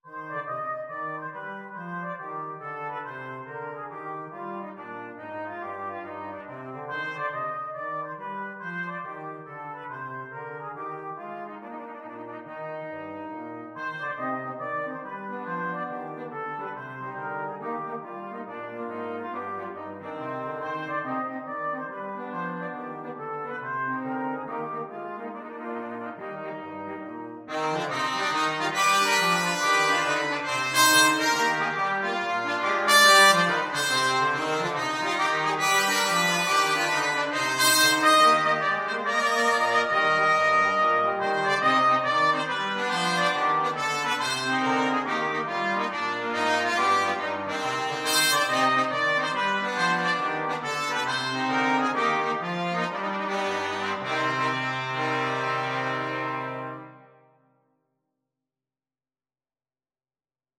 Trumpet 1Trumpet 2French HornTrombone
4/4 (View more 4/4 Music)
Quick Swing = c. 140
Jazz (View more Jazz Brass Quartet Music)
Rock and pop (View more Rock and pop Brass Quartet Music)